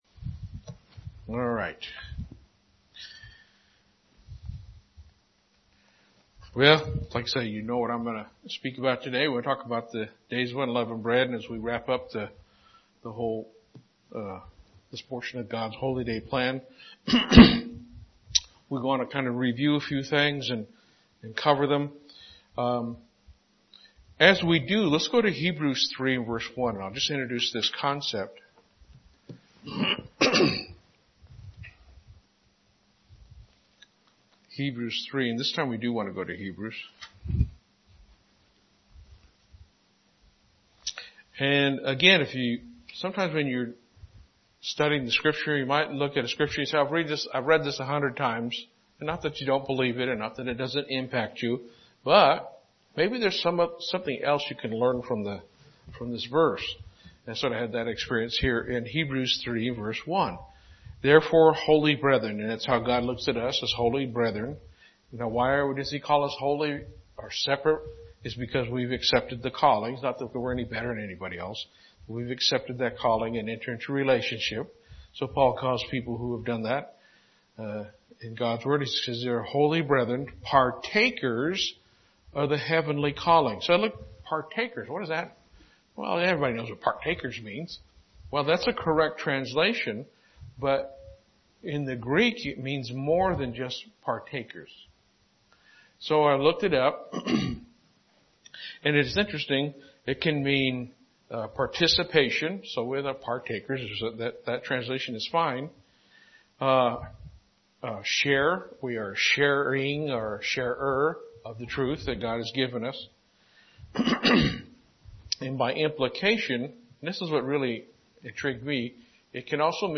Given in Lubbock, TX
Holy Day Services Studying the bible?